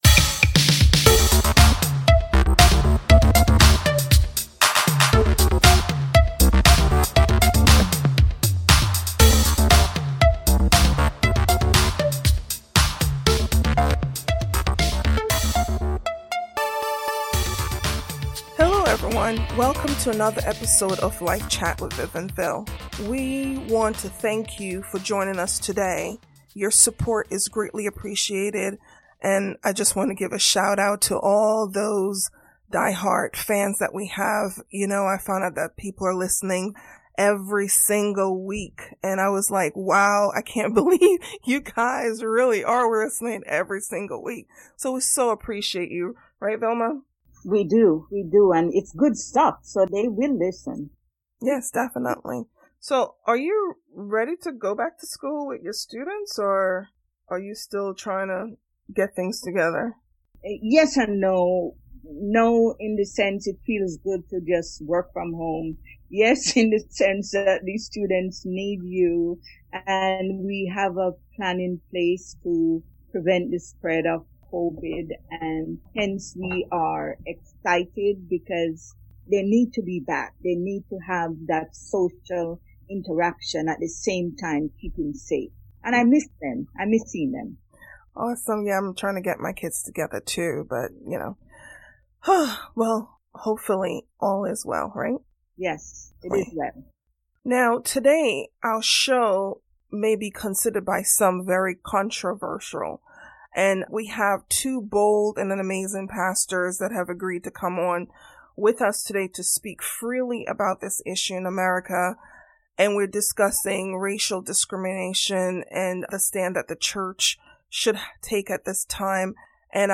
Pastors talking about the social unrest in America and the role of Church in this seasons.